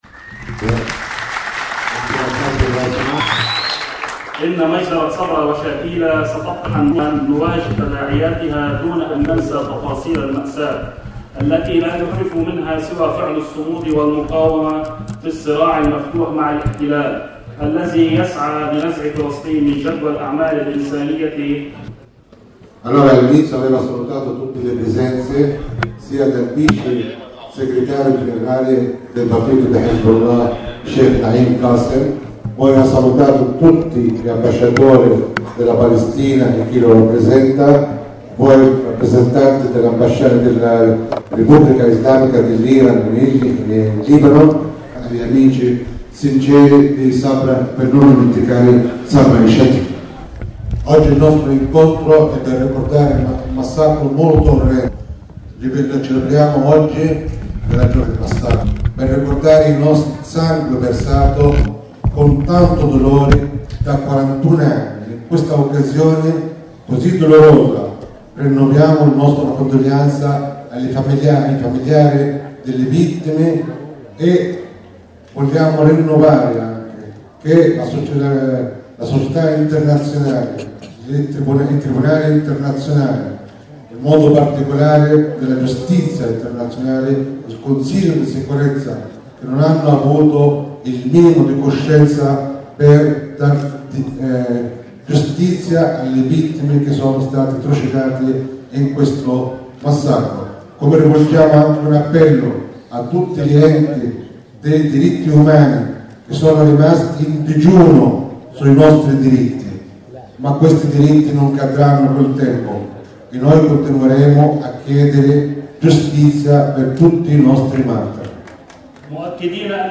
Lasciati momentaneamente i saluti e gli abbracci, la cerimonia ha inizio.